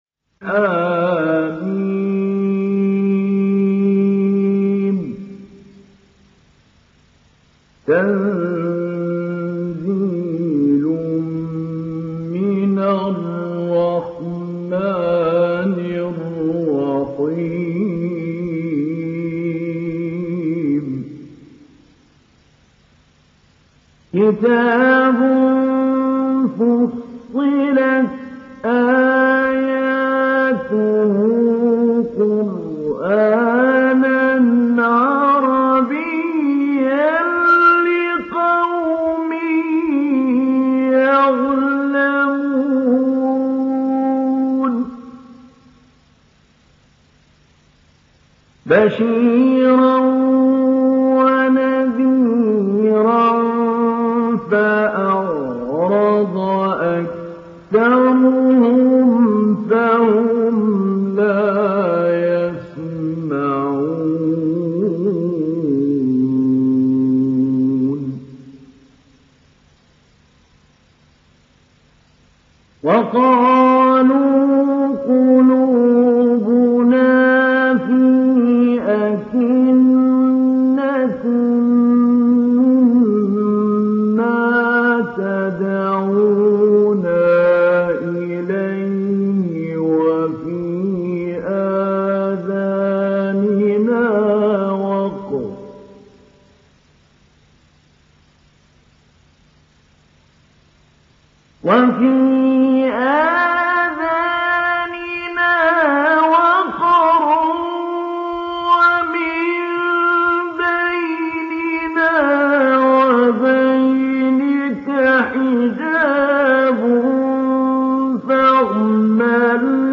تحميل سورة فصلت mp3 بصوت محمود علي البنا مجود برواية حفص عن عاصم, تحميل استماع القرآن الكريم على الجوال mp3 كاملا بروابط مباشرة وسريعة
تحميل سورة فصلت محمود علي البنا مجود